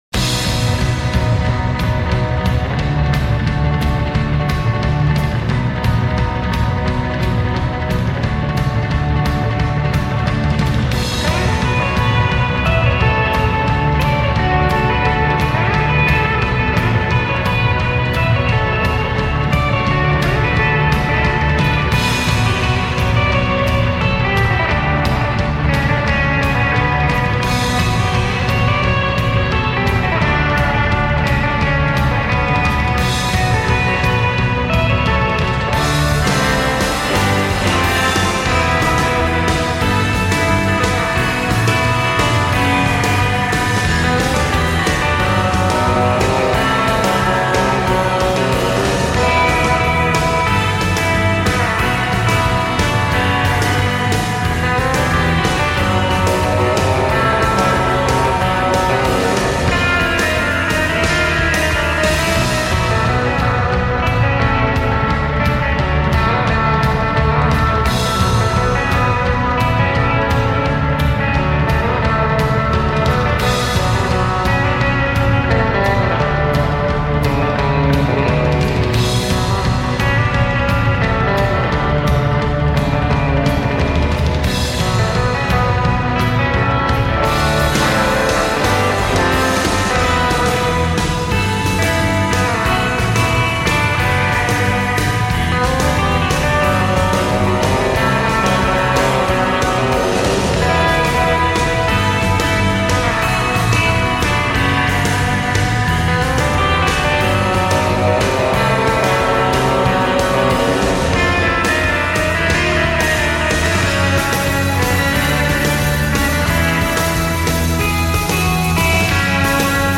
Tag: surf rock